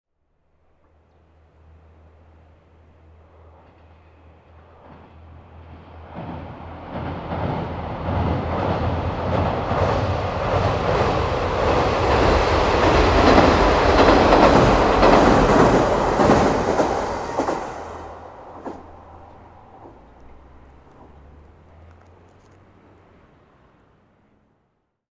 福渡駅を出て岡山（右）へ向かうキハ４０系の列車。